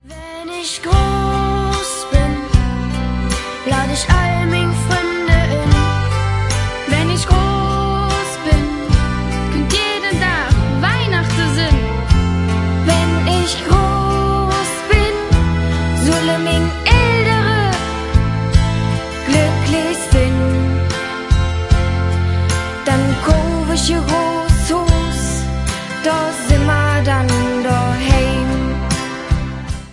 Advents- und Weihnachtsleedcher in Kölner Mundart